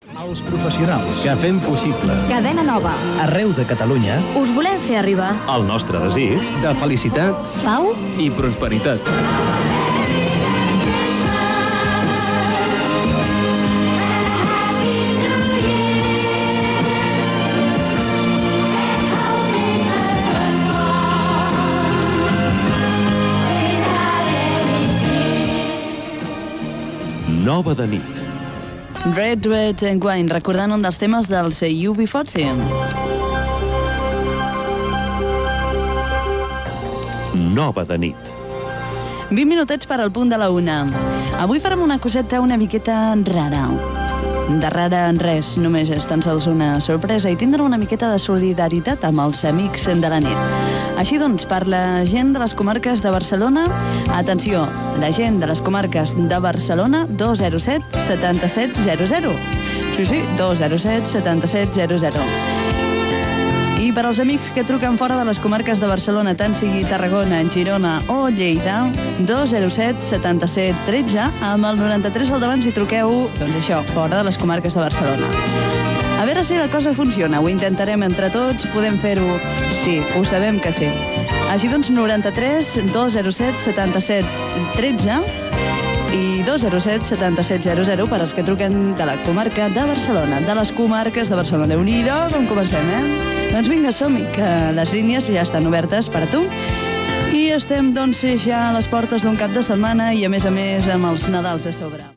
Felicitació nadalenca, indicatiu del programa, hora, telèfons de contacte
Musical
FM